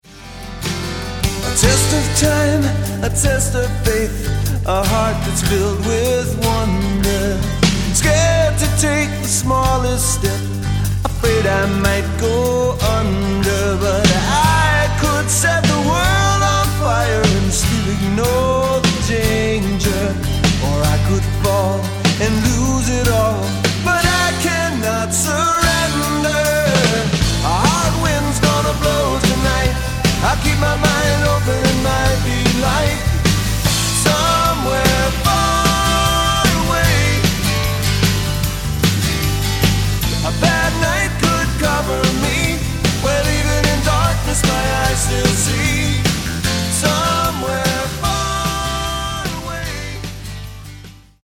Acoustic & Electric Guitars, Vocals
Acoustic Guitars, Keyboards, Percussion, Vocals